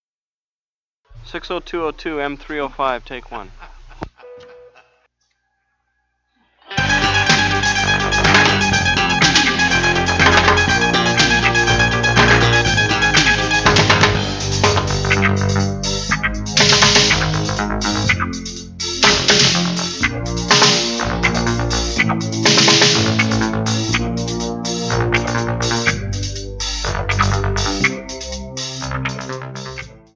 UPC: Soundtrack